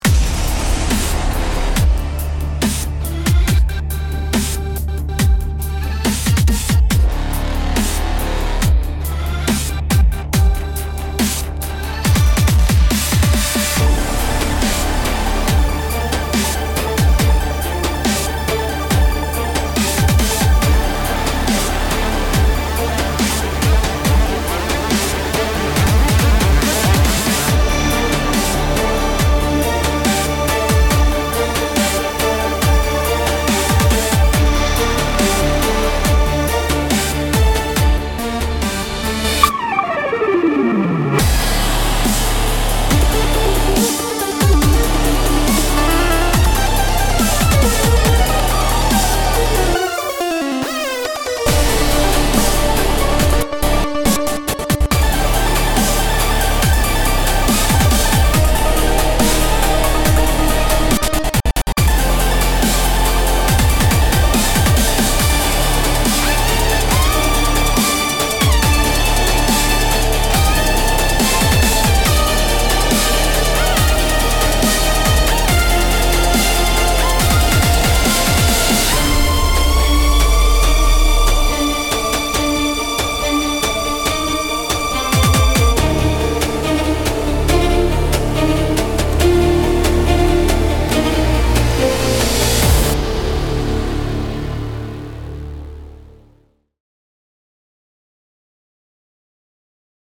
Ignite your productions with Supernova, a cutting-edge soundbank for Reveal Sound Spire delivering 100 meticulously crafted Cinematic EDM Evolving Leads.
Each preset is sculpted to evolve dynamically, shifting across time with lush modulation, atmospheric textures, and commanding presence.
• Powerful, aggressive and emotional tones, lush cinematic textures
• * The video and audio demos contain presets played from Supernova sound bank, every single sound is created from scratch with Spire.
• * All sounds of video and audio demos are from Supernova (except drums and additional arrangements).